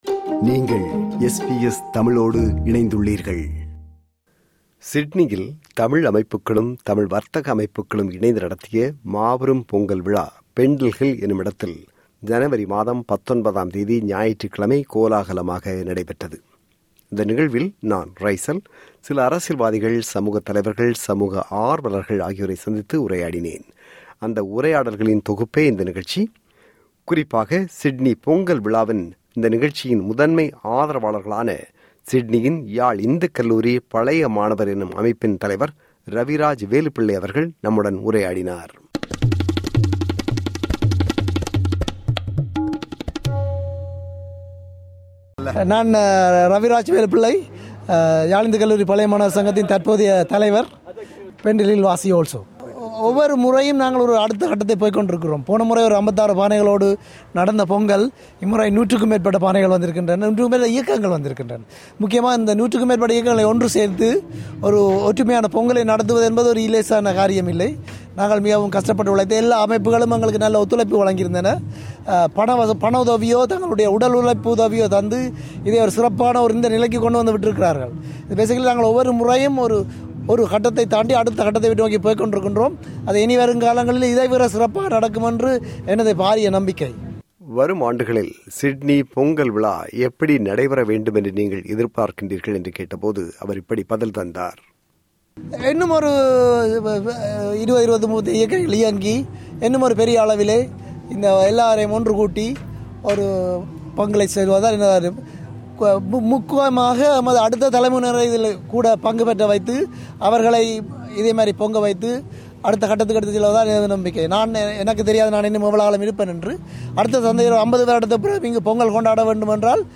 சிட்னியில் தமிழ் அமைப்புகளும், தமிழ் வர்த்தக அமைப்புகளும் இணைந்து நடத்திய மாபெரும் பொங்கல் விழா Pendle Hill எனுமிடத்தில் ஜனவரி மாதம் 19 ஆம் தேதி – ஞாயிற்றுக்கிழமை கோலாகலமாக நடைபெற்றபோது சில சமூகத் தலைவர்கள் மற்றும் சமூக ஆர்வலர்களுடன் உரையாடினோம்.